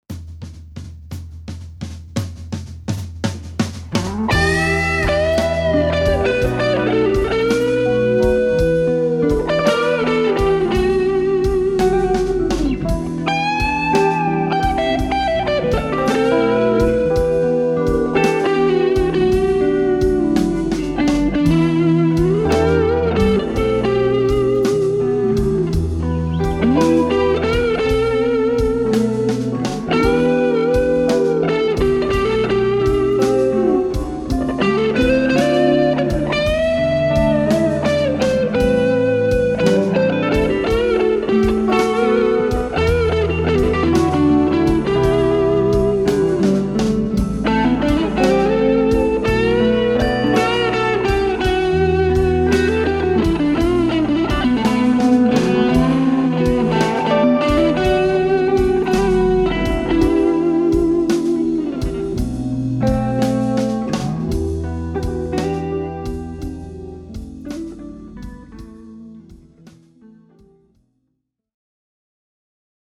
In my impending review, I’ll have more clips to share, but here’s a “torch song” that I’ve been working on that features the StealthPedal in action as an audio interface:
Except for the drum loop, all the instruments were recorded using the StealthPedal as the audio interface. I did a line-in directly for the electric piano. For the guitar parts, the amps are both software amps! For the rhythm part I used a Fender Champ 600 with a virtual compressor rack plug-in and my Strat plugged directly into the StealthPedal. For the lead part, I plugged into my pedal board and used my Tone Freak Effects Abunai 2 overdrive, and a Hardwire RV-7 Reverb. For the amp, I used a ’59 Bassman model.